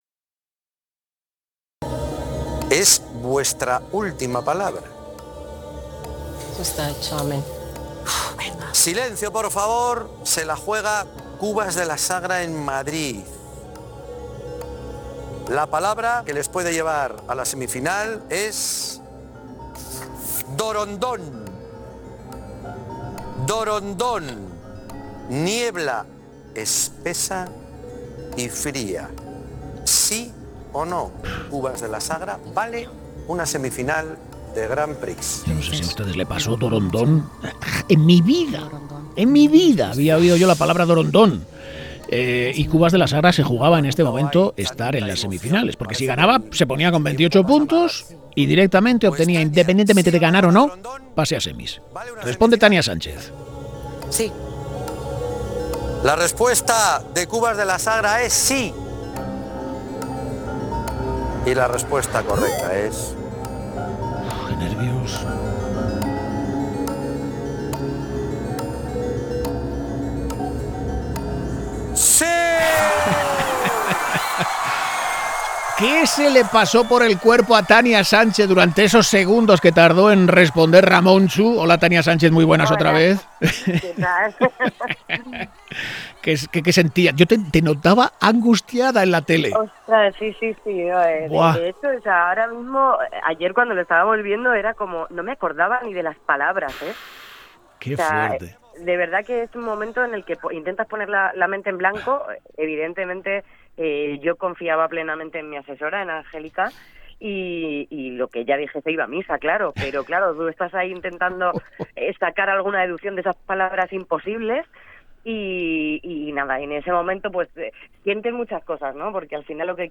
Entrevistamos a Tania Sánchez, teniente de alcalde del Ayuntamiento de Cubas de la Sagra